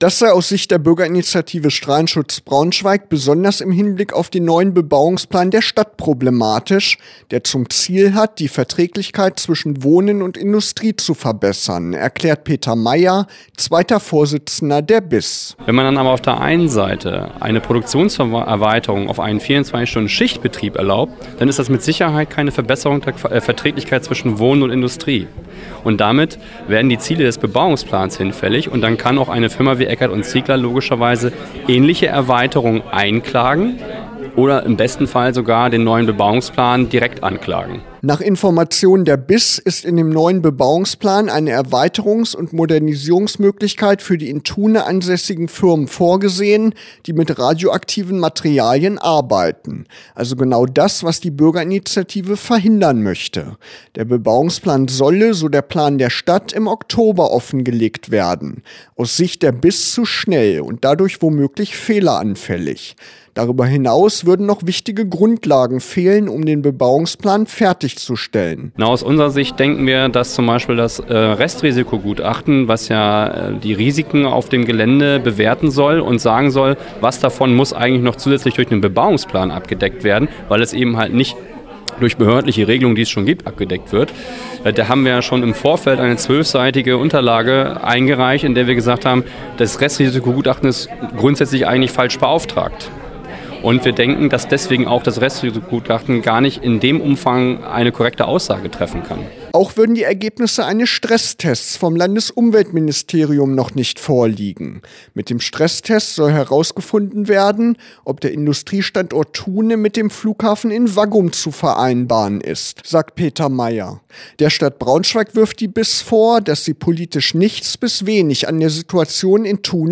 Interview von der Info-Veranstaltung zum Rechtshilfefonds